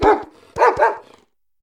Cri de Briochien dans Pokémon HOME.